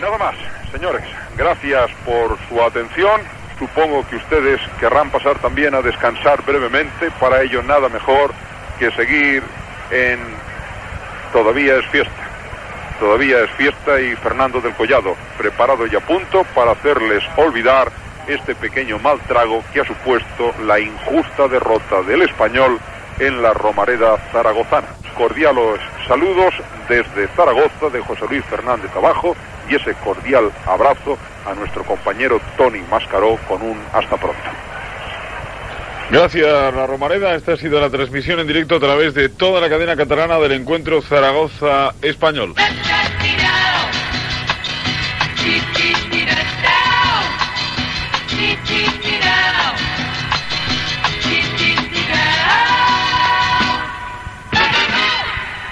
Transmissió, des de Saragossa, del partit de la lliga masculina de la primera divisió de futbol entre el Zaragoza i el Real Club Deportivo Espanyol.
Comiat de la transmissió.
Esportiu